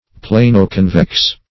Plano-convex \Pla"no-con"vex\, a. [Plano- + convex.]